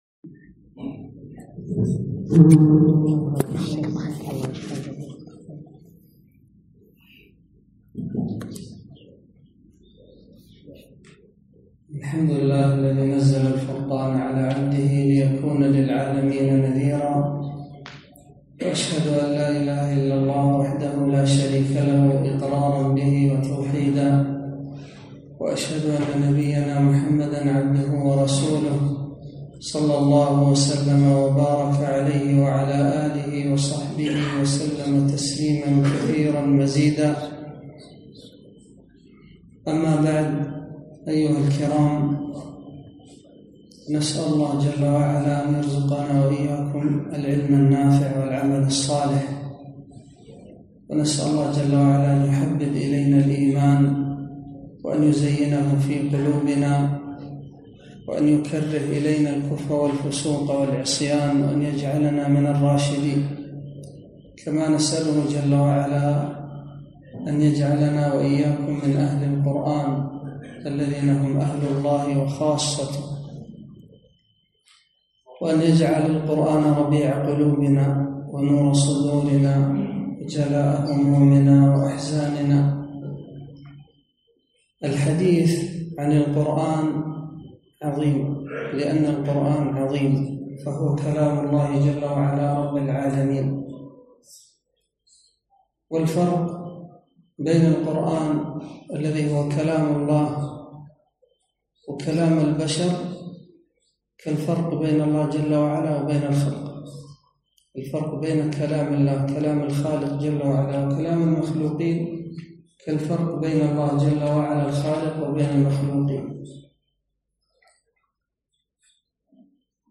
كلمة - كيف أتدبر القرآن؟